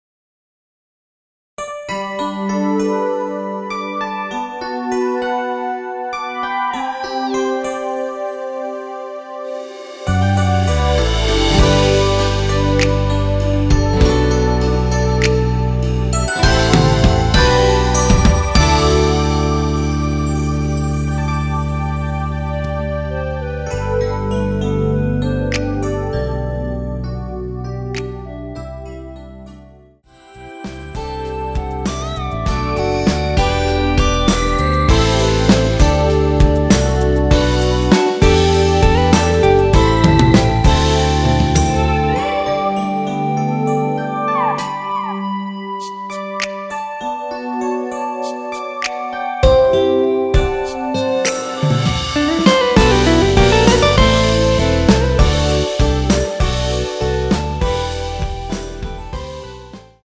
원키에서(+5)올린 2절 삭제한 멜로디 포함된 MR입니다.
앞부분30초, 뒷부분30초씩 편집해서 올려 드리고 있습니다.
중간에 음이 끈어지고 다시 나오는 이유는